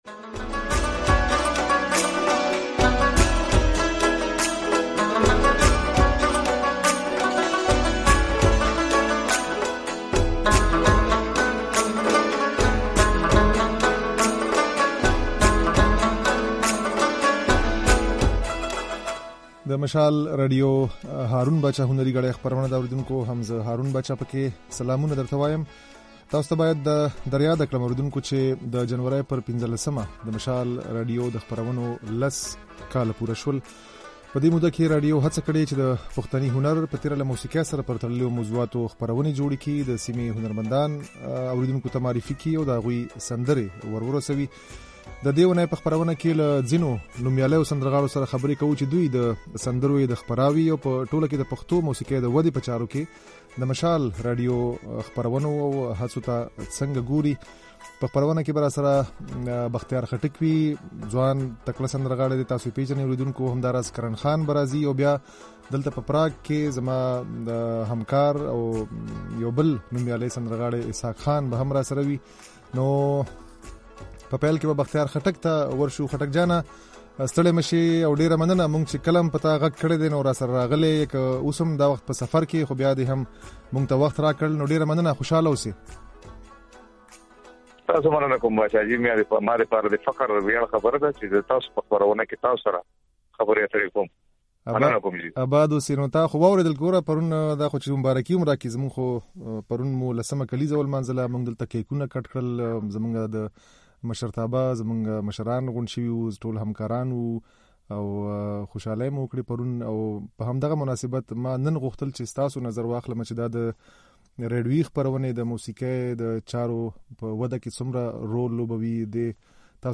د سندرغاړو دا خبرې او سندرې يې د غږ په ځای کې اورېدای شئ.